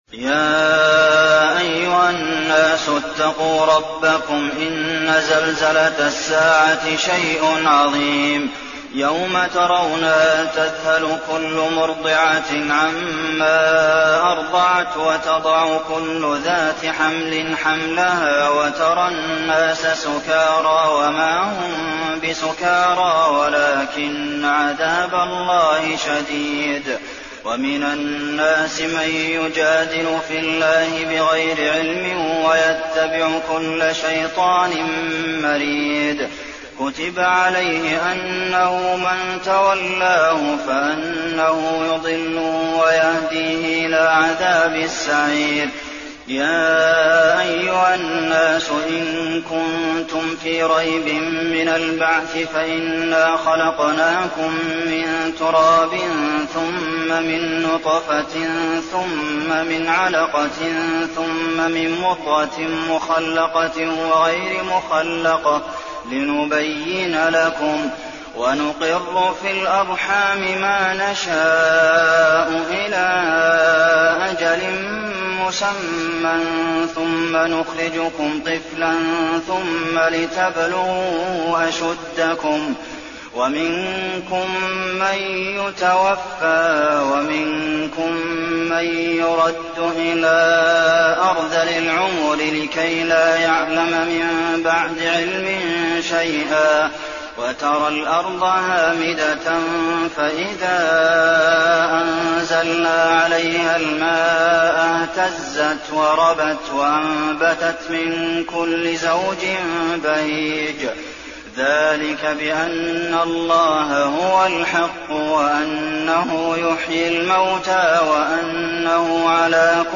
تراويح الليلة السادسة عشر رمضان 1422هـ سورة الحج كاملة Taraweeh 16 st night Ramadan 1422H from Surah Al-Hajj > تراويح الحرم النبوي عام 1422 🕌 > التراويح - تلاوات الحرمين